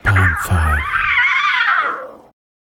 Playful Cub Sounds Bouton sonore